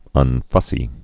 (ŭn-fŭsē)